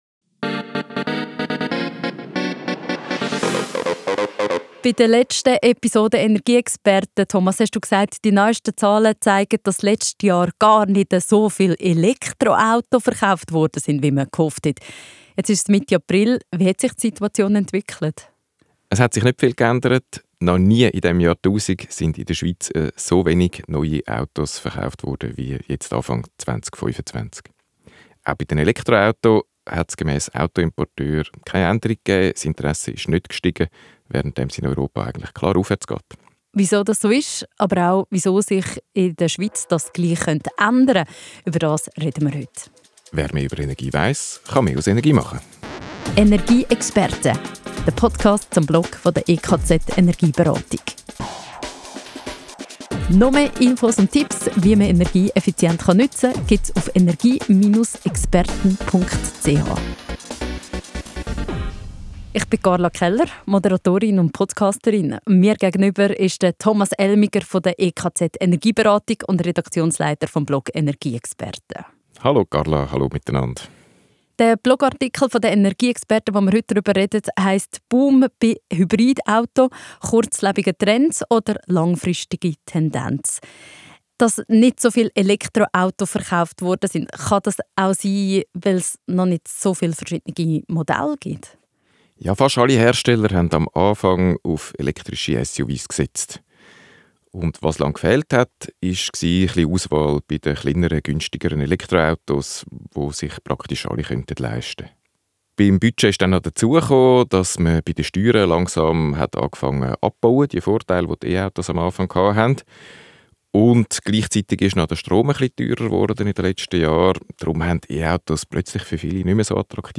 EP14-Hybridautos-Talk.mp3